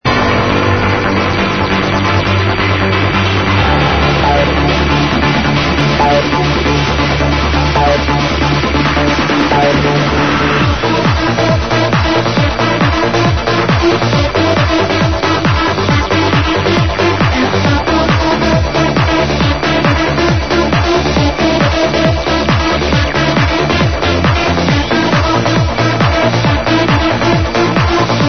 One of the very few trance tunes i actually enjoy.